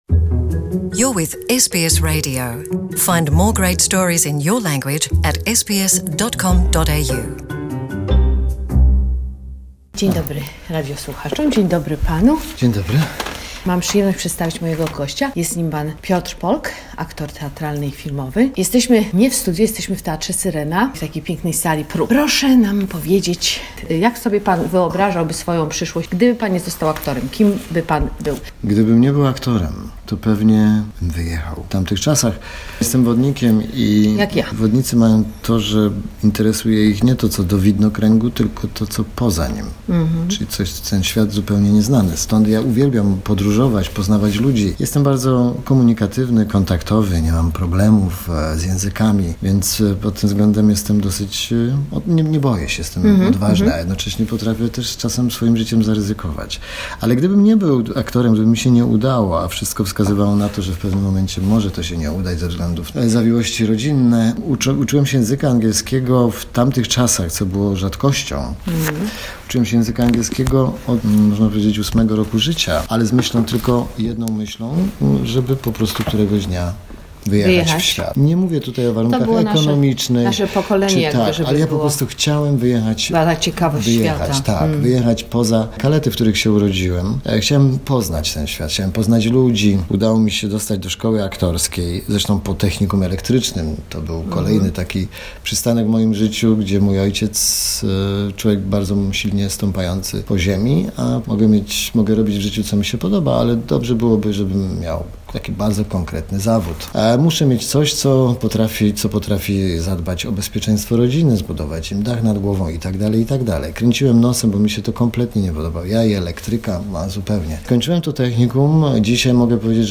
The conversation with popular Polish actor Piotr Polk.